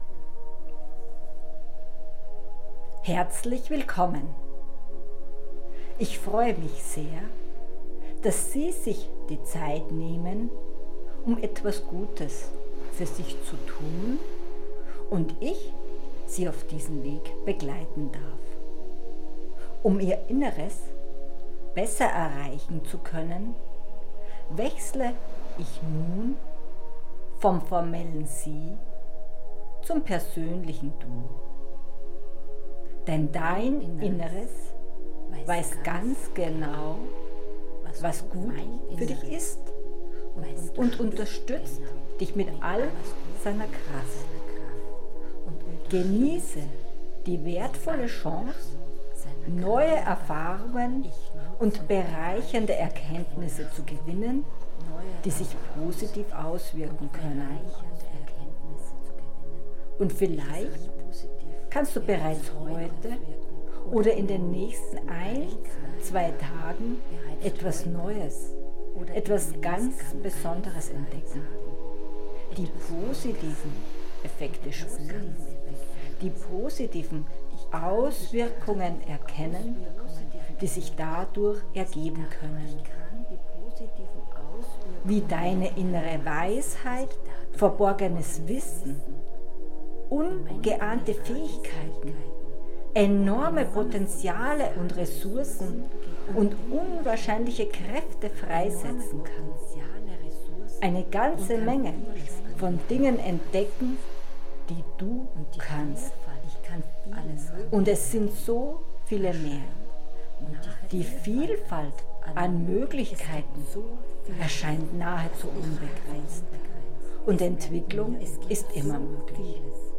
Als besonders effektiv hat sich das Hören über hochwertige Kopfhörer erwiesen, da die eingebetteten Klangmuster noch intensiver wirken können.